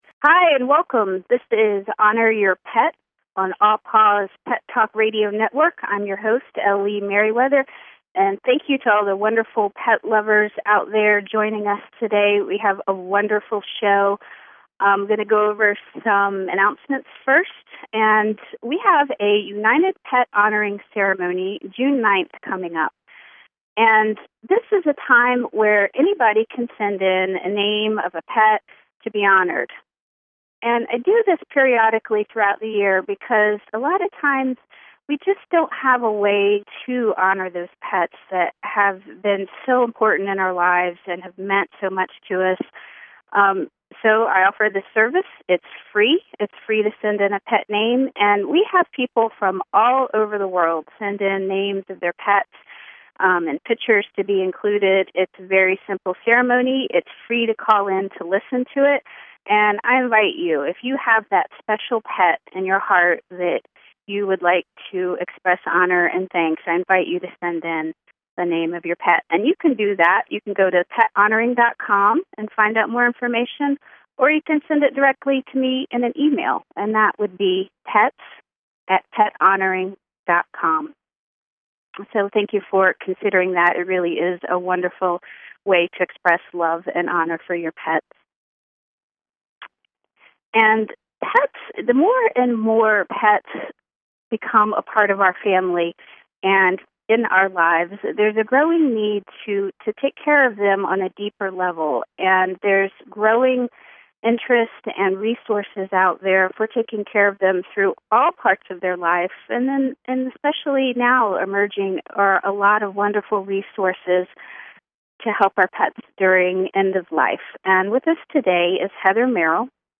Honor Your Pet is a talk radio show covering all things which honor our pets and our relationship with them. These include innovative and holistic pet care topics as well as addressing the difficult, but honorable end-of-life times and healing grief from pet loss.